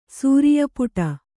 ♪ sūriya puṭa